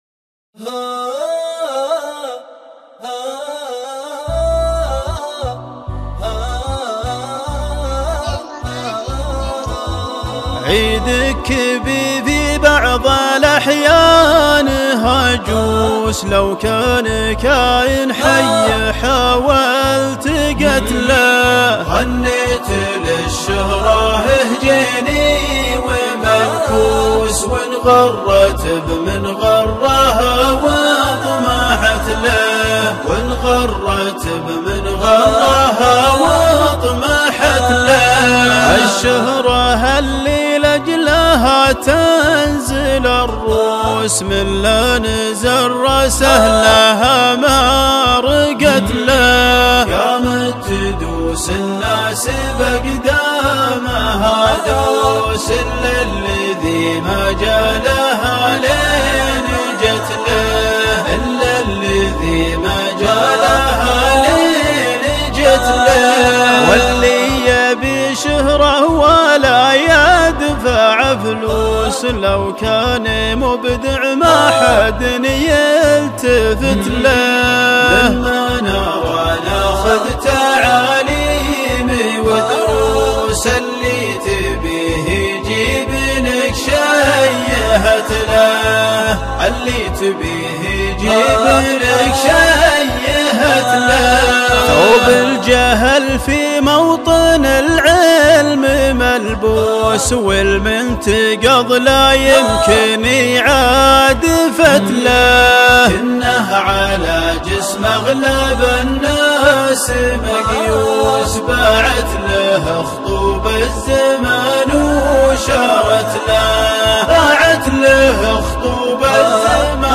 بدون ايقاع